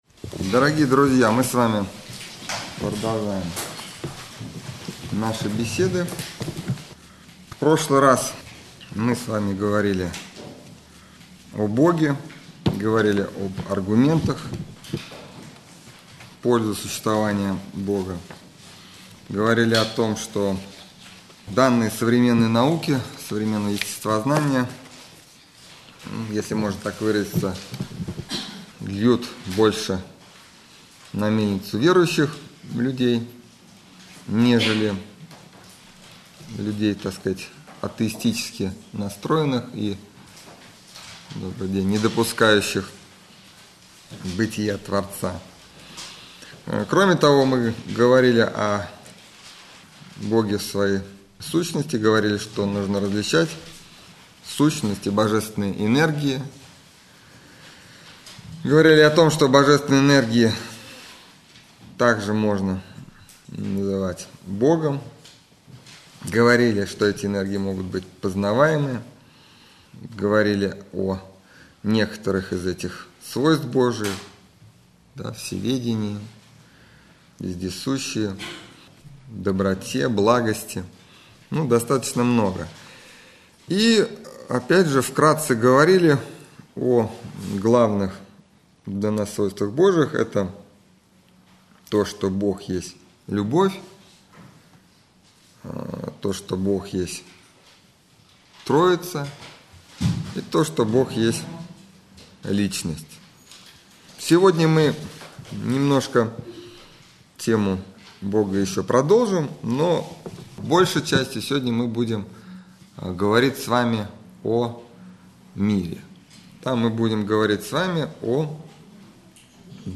Общедоступный православный лекторий